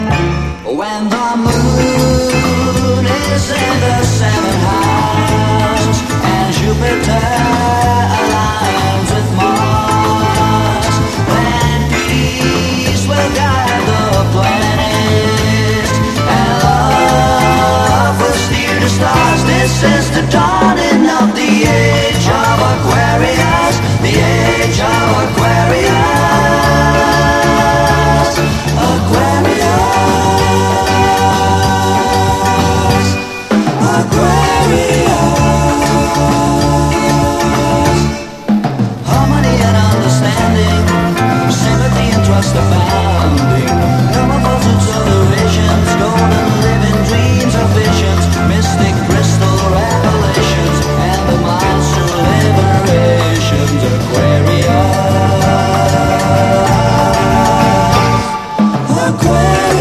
NEO CLASSICAL